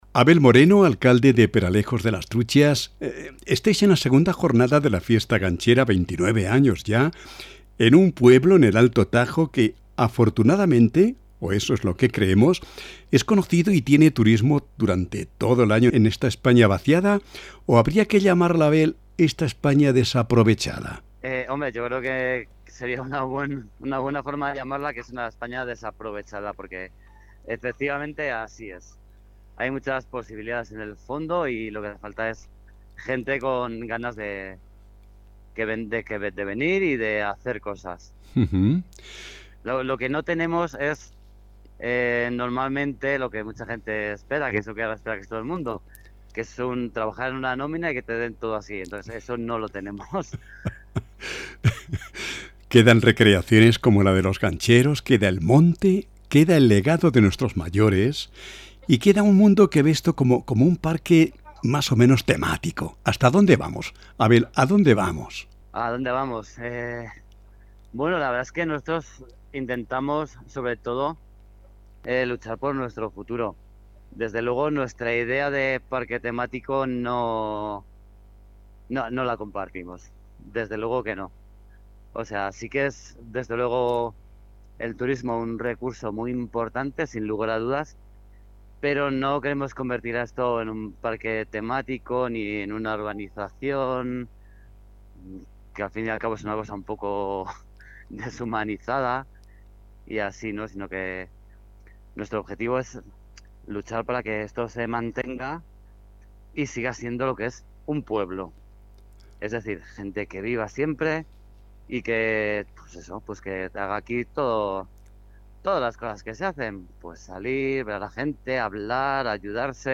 Entrevistamos a Abel Moreno, alcalde de Peralejos de las Truchas